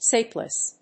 アクセント・音節sáp・less
音節sap･less発音記号・読み方sǽpləs
sapless.mp3